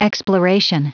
Prononciation du mot exploration en anglais (fichier audio)
exploration.wav